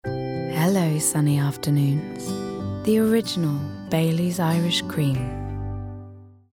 20/30's London/Neutral,
Contemporary/Natural/Earthy
Commercial Showreel